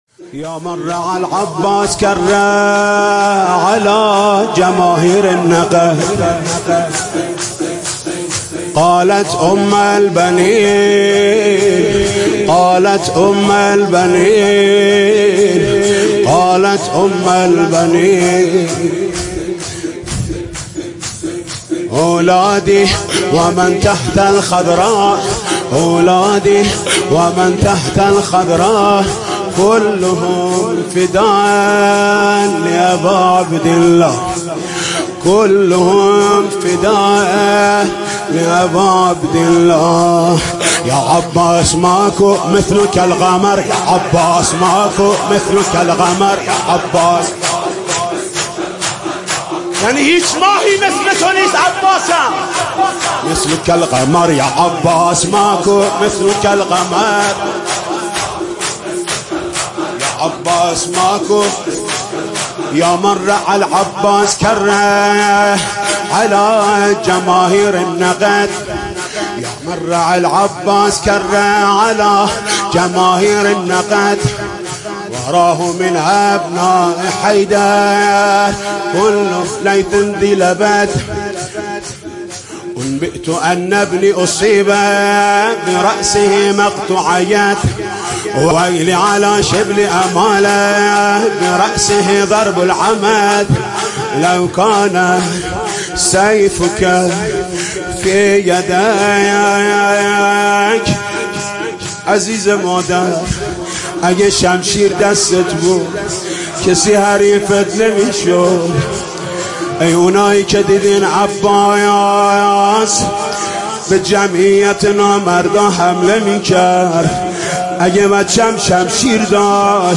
مداحی جديد
شور